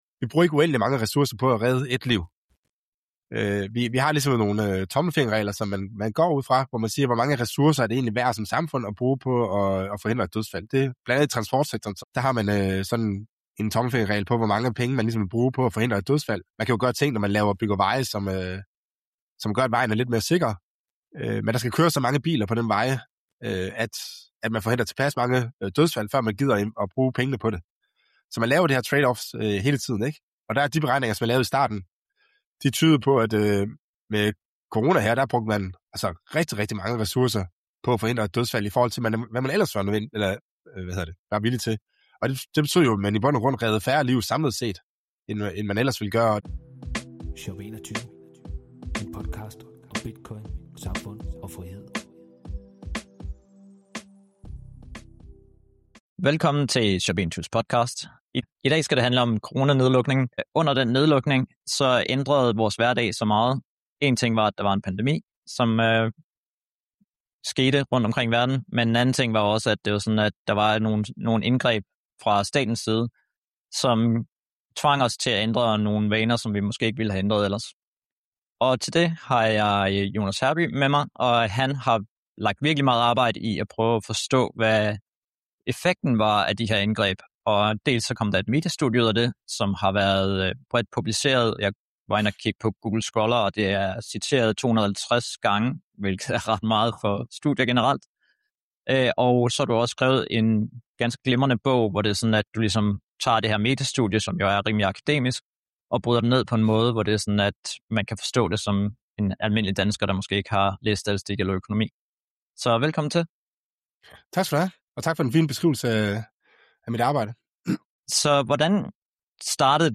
Samtalen fremhæver vigtigheden af at lære af fortiden for at håndtere fremtidige kriser mere effektivt.